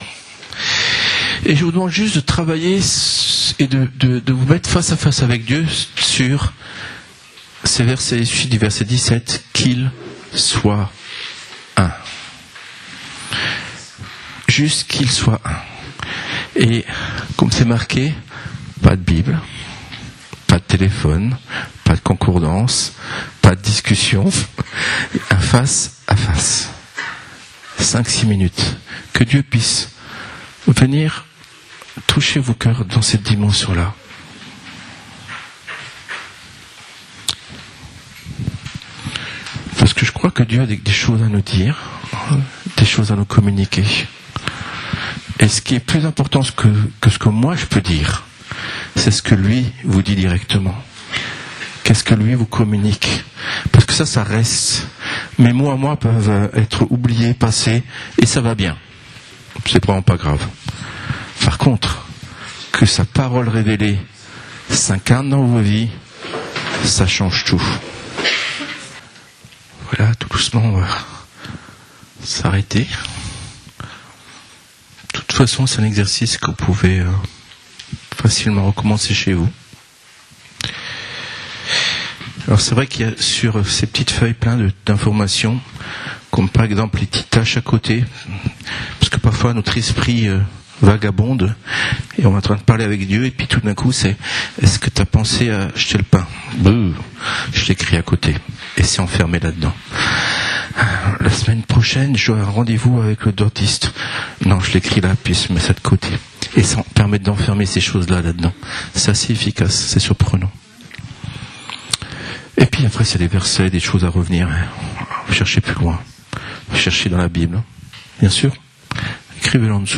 Type de service: Culte du dimanche